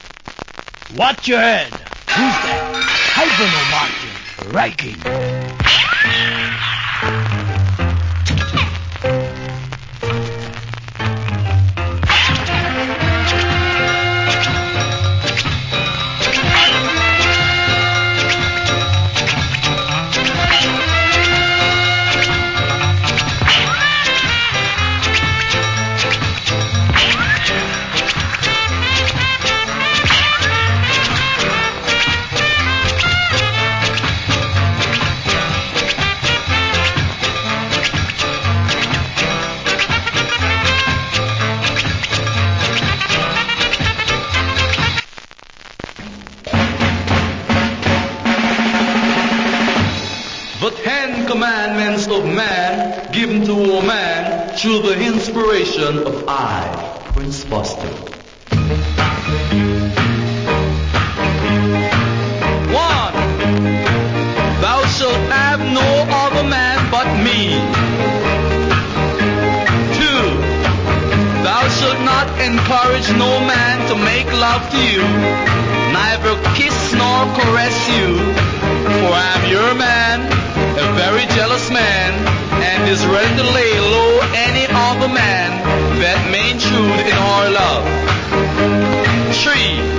Killer Ska Inst.